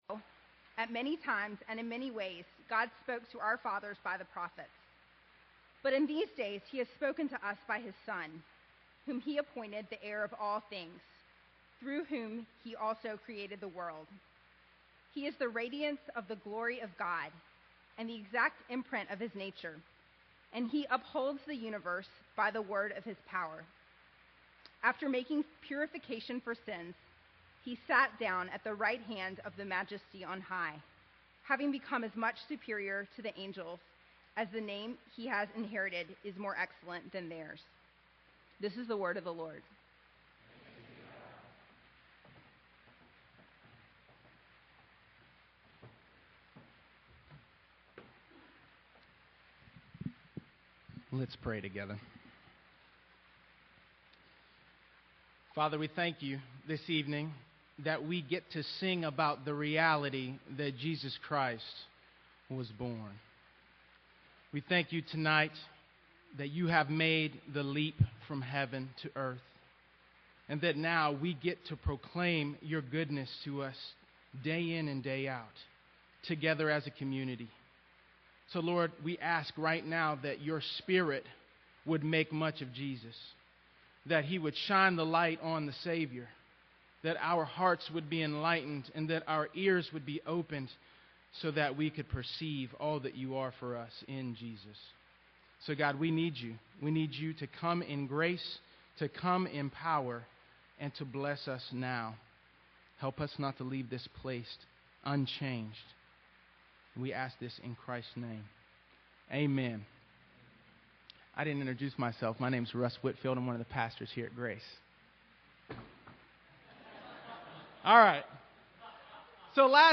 Download MP3 Share Related Sermons Why Do We Seek the Living Among the Dead?